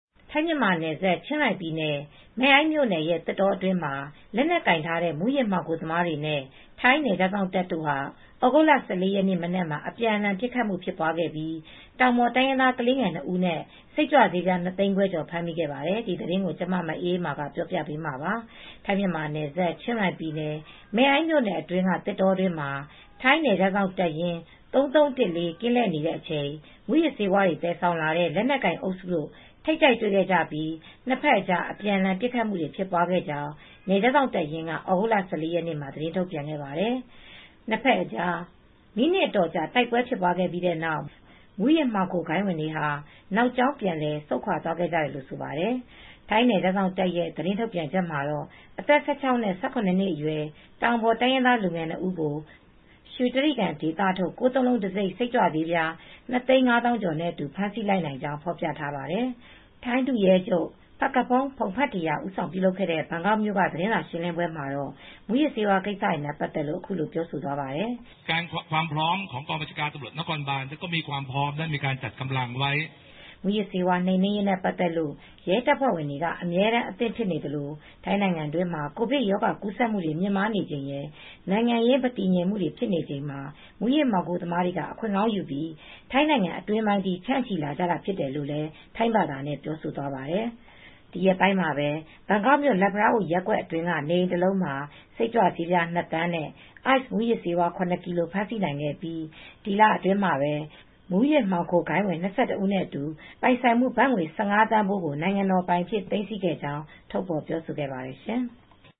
ထိုင်းဒုရဲချုပ် Phakphong Pongphetra ဦးဆောင်ပြုလုပ်တဲ့ ဘန်ကောက်မြို့က သတင်းစာရှင်းလင်းပွဲမှာတော့ မူယစ်ဆေးဝါး ကိစ္စတွေနဲ့ ပတ်သက်လို့ အခုလို ပြောသွားပါတယ်။